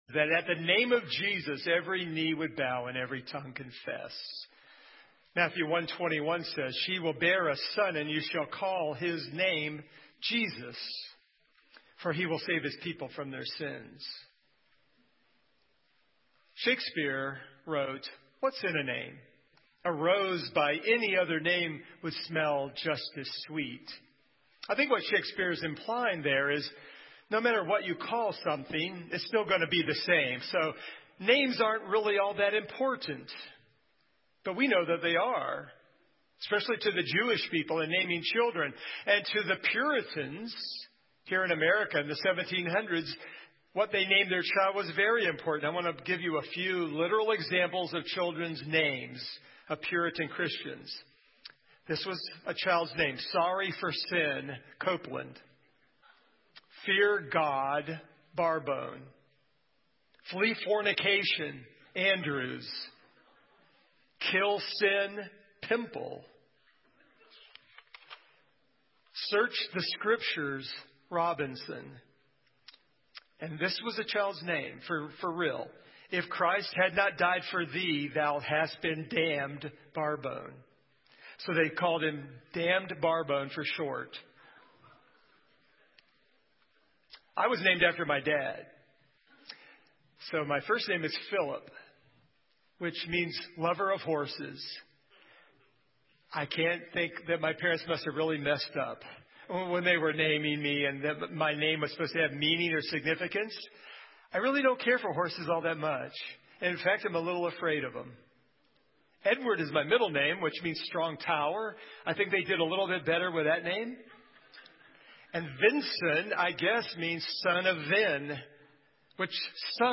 Service Type: Sunday Morning
Download Files Notes Topics: Christmas , Person of Jesus share this sermon « Christ Supreme What’s So Special About Christmas?